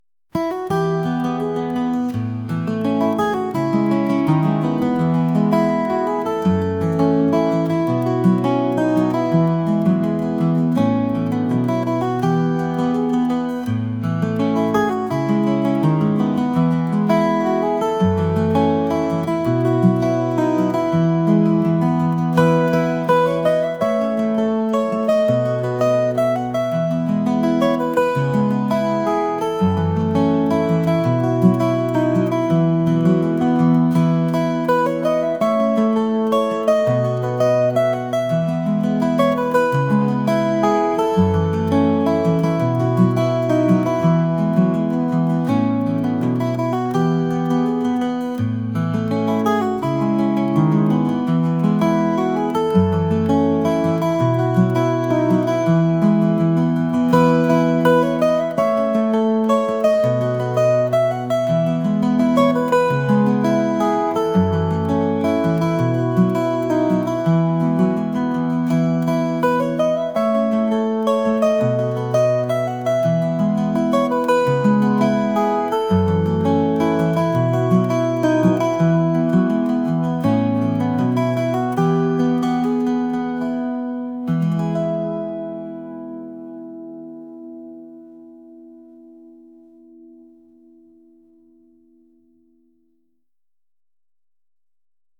acoustic | folk | rock